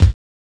KNOCKER.wav